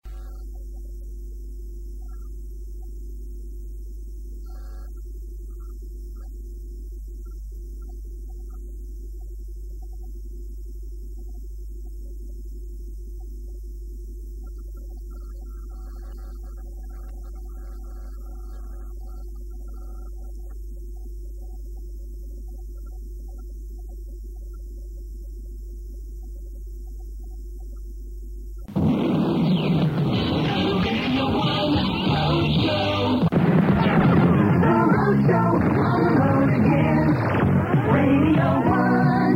The beginning of a Radio 1 Roadshow from Summer 1988 with Simon Mayo